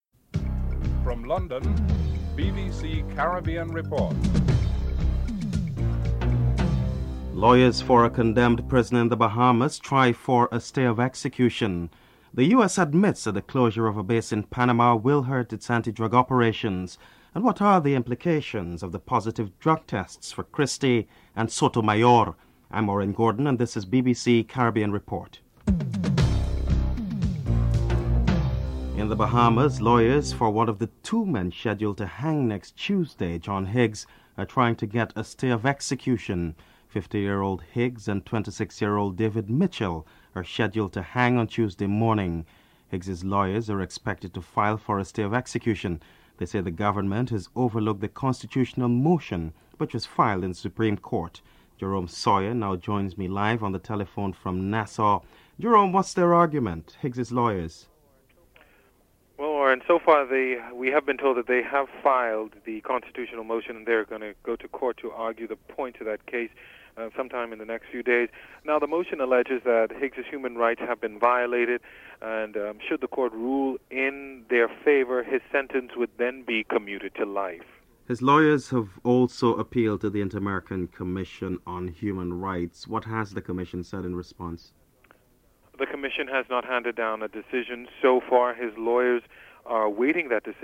Headlines
BBC Reporter appraises the issue on drugs in sports and testing procedures (12:32 – 15:26)